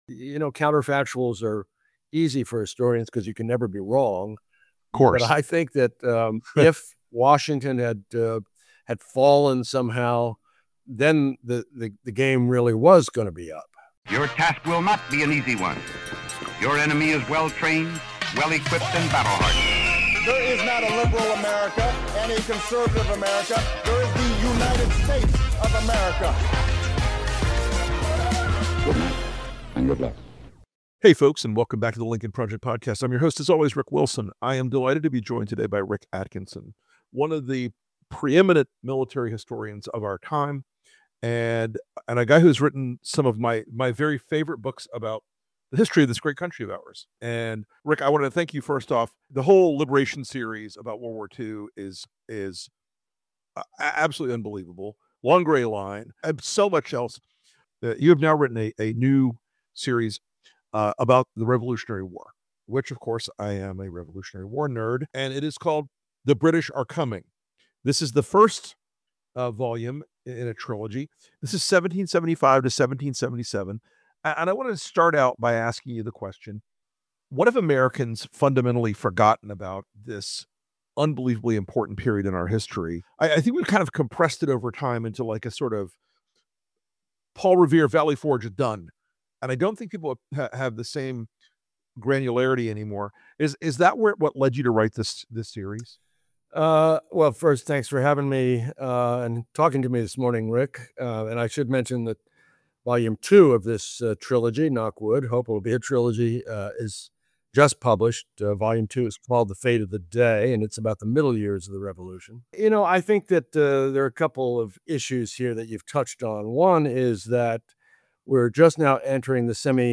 In this special episode, Pulitzer Prize-winning historian Rick Atkinson joins Rick Wilson (who is also a history buff) to re-examine the history of the Revolutionary War and how the grit of great Americans ultimately secured our country's freedom.